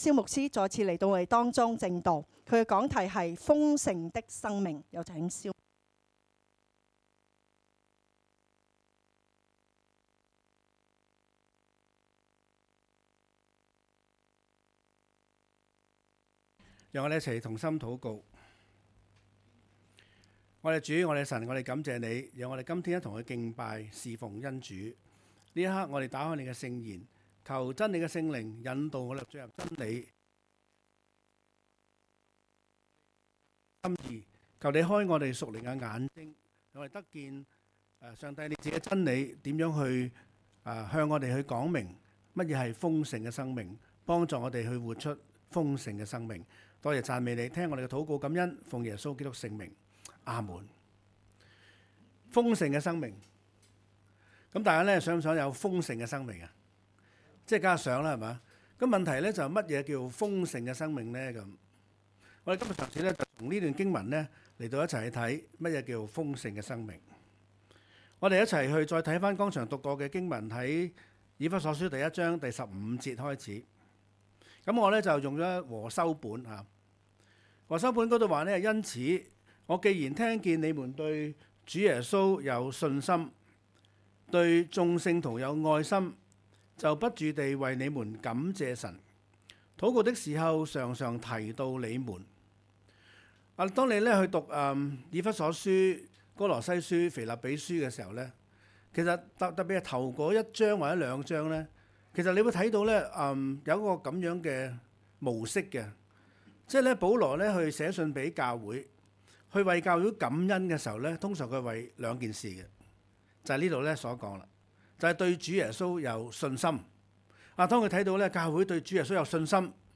講道 ：豐盛的生命